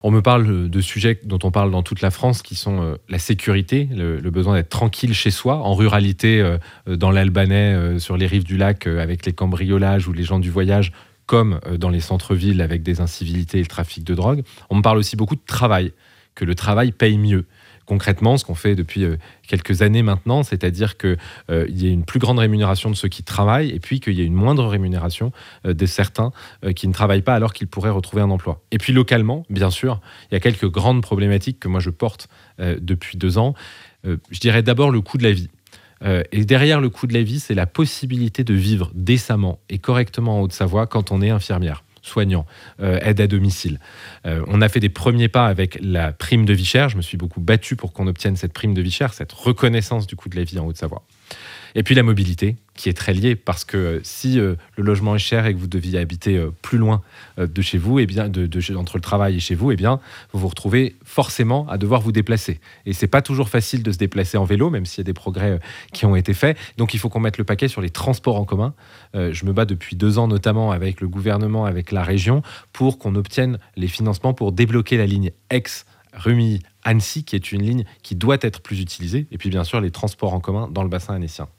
Voic les interviews des 8 candidats de cette 2eme circonscription de Haute-Savoie (par ordre du tirage officiel de la Préfecture) :